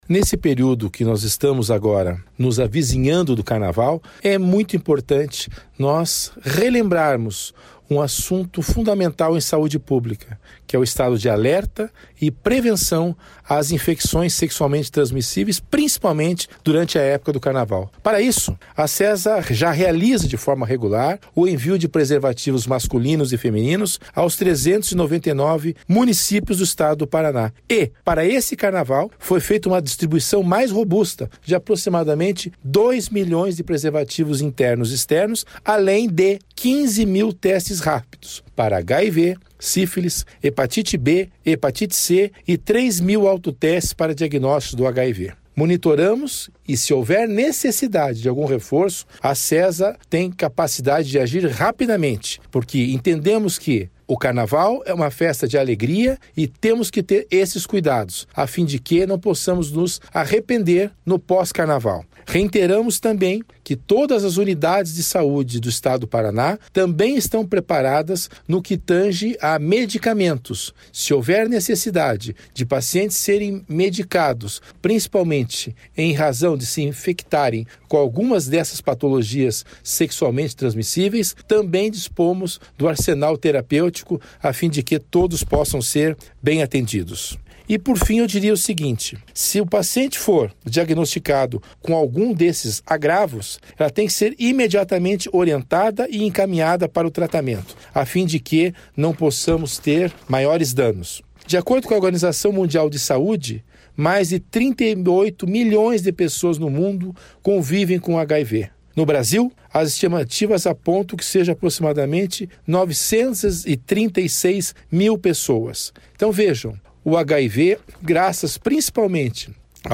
Sonora do secretário da Saúde, César Neves, sobre a importância da prevenção às Infecções Sexualmente Transmissíveis durante o Carnaval